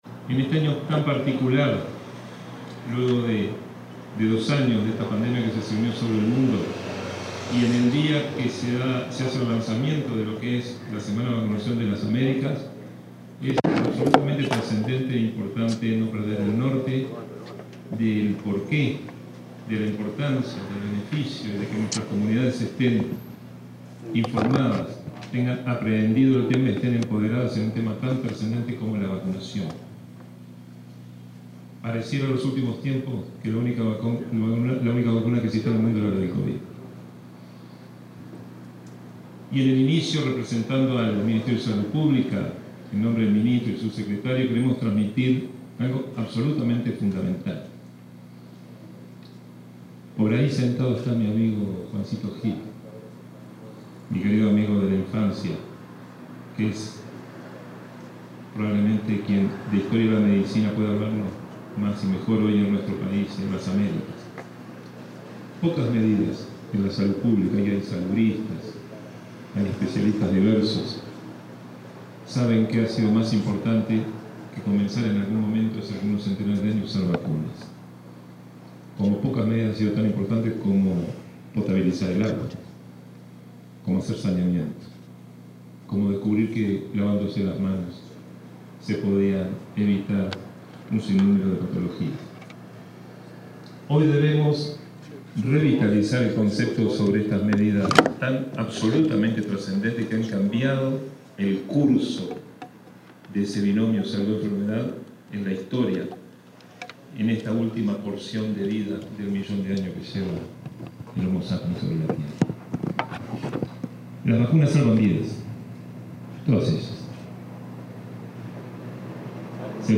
Conferencia de prensa por el lanzamiento de la 20.ª Semana de Vacunación de las Américas
conferencia.mp3